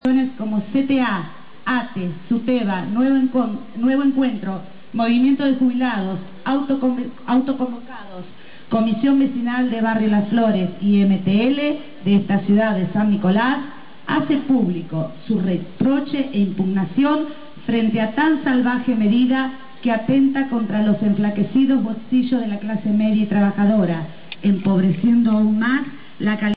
En el marco de la Jornada Nacional de Movilizaci�n convocada por la CTA se realiz� en San Nicol�s una Marcha Regional con la participaci�n de m�s de 200 compa�eros. Se puede escuchar audio de la jornada.